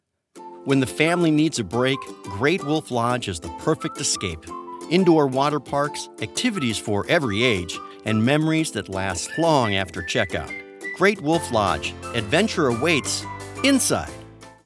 Promo Voiceover
Energetic. Confident. Built to Get Audiences Watching.
Promo Demos